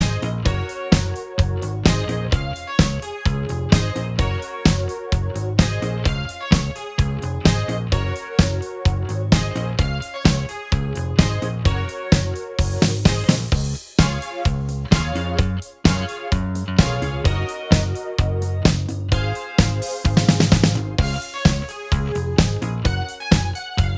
No Drum Kit Pop (1980s) 3:47 Buy £1.50